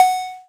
edm-perc-16.wav